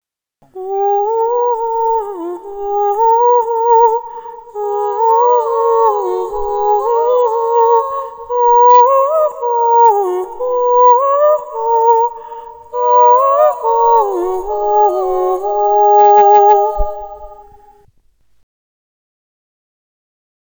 Genre: Medieval Folk / Ambient